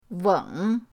weng3.mp3